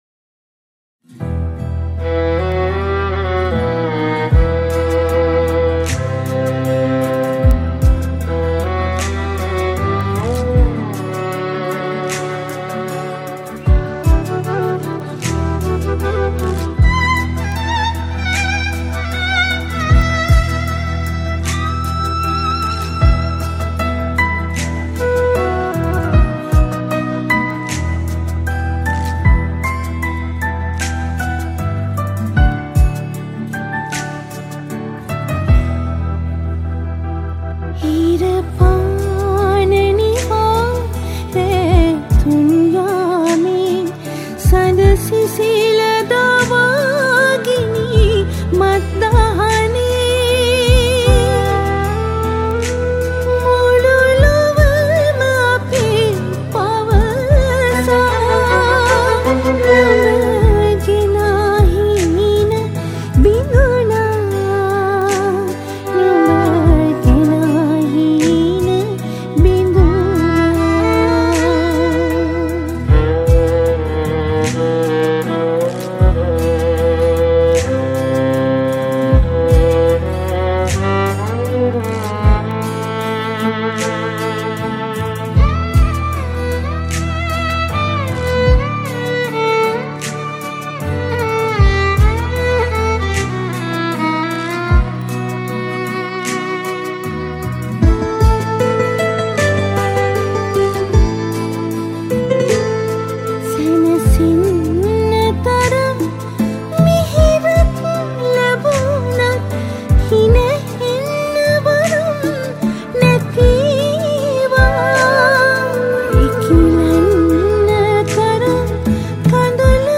Computerized Music Arrangement
Guitar
Violin/Viola
Flute